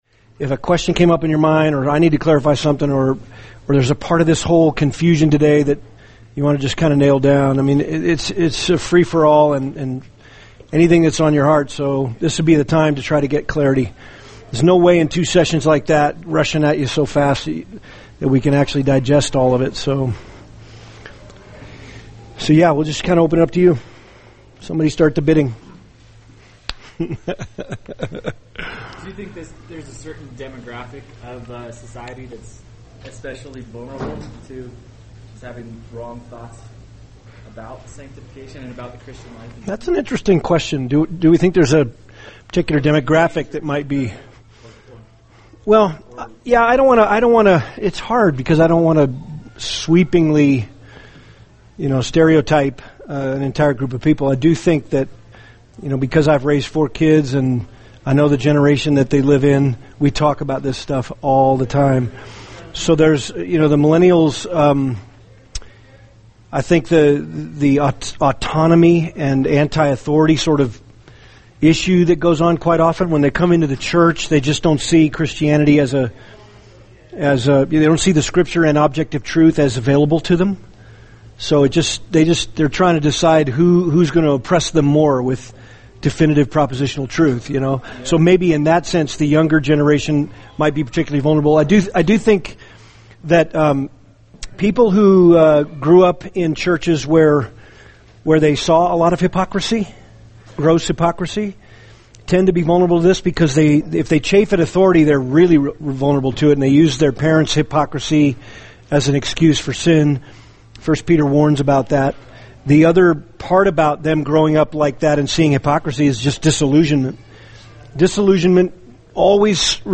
[seminar] Trust & Obey: Faith, Feelings, and Spiritual Growth (3 – Q&A) | Cornerstone Church - Jackson Hole